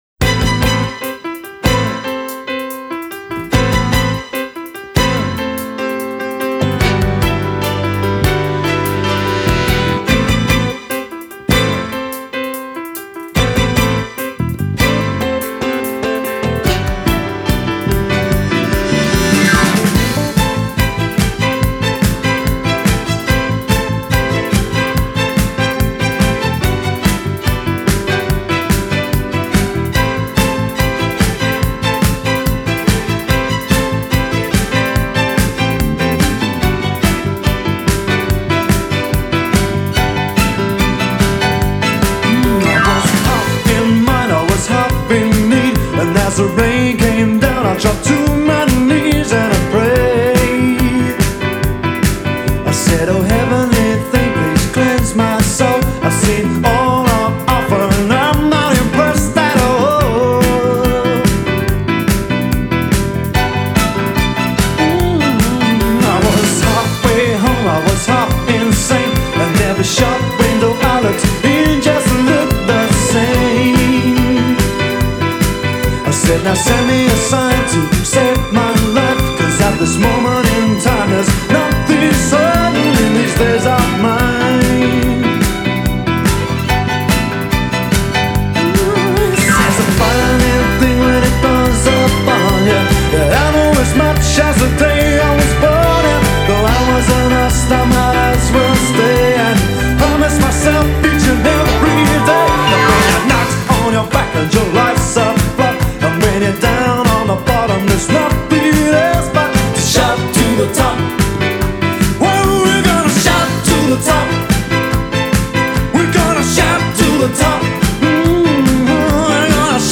A frothy and enduring number